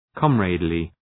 Προφορά
{‘kɒmrædlı}